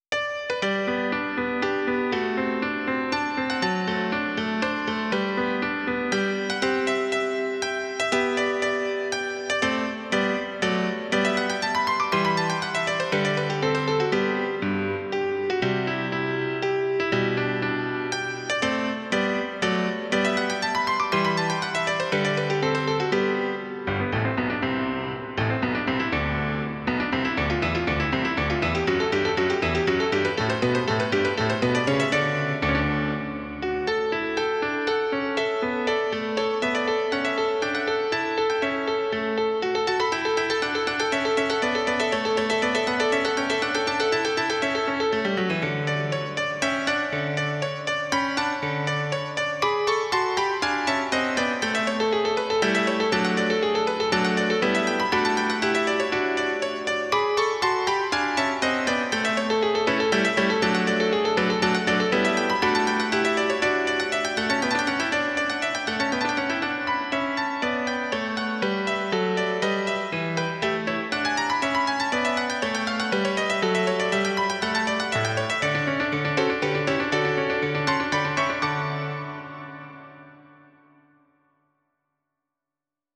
música clásica
sonata